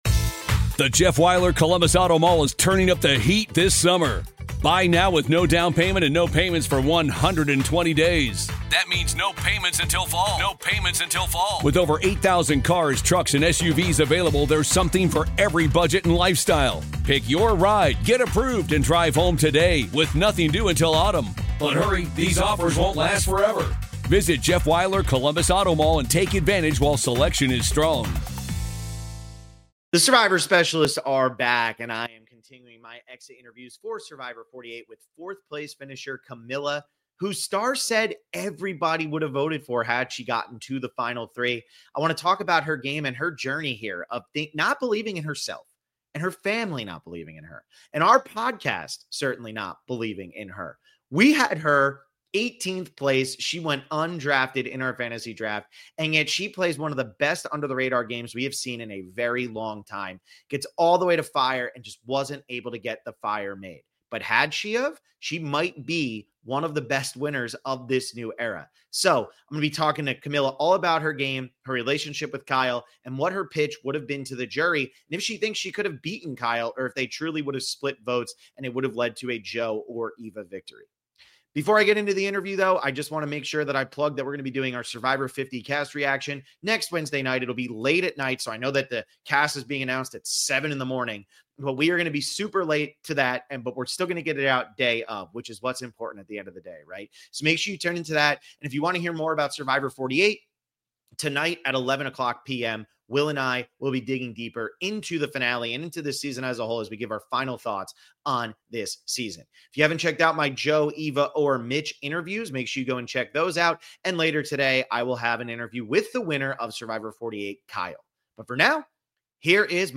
Survivor 48 Post Game Interview w